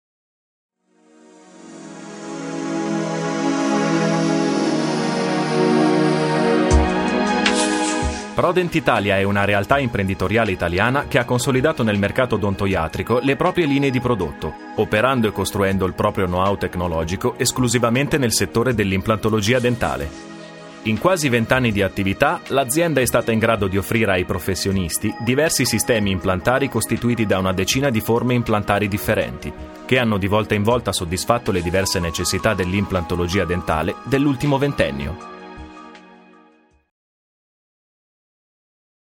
Voce baritonale e calda.
Sprechprobe: Industrie (Muttersprache):
Warm voice, precision, speed and efficacy.